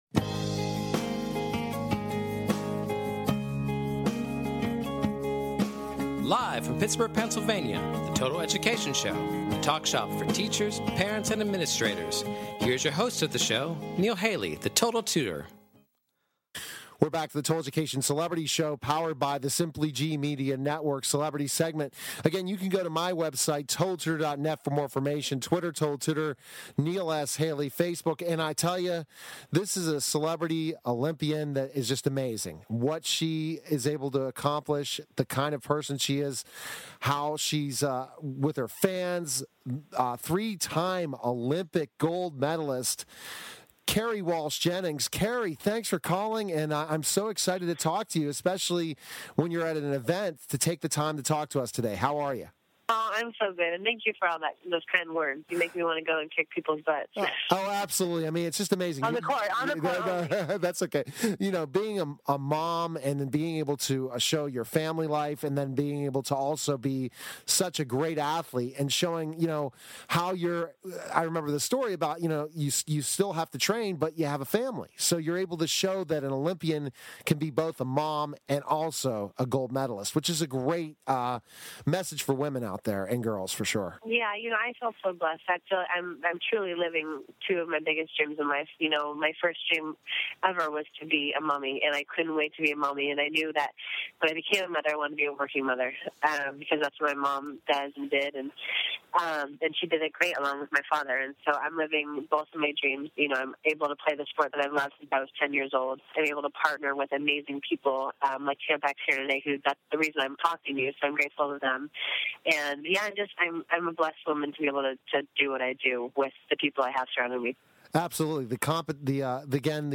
Total Education Celebrity Show {also known as "The Total Education Hour" is an educational talk show that focuses on the listeners' needs. Catch weekly discussions focusing on current education news at a local and national scale.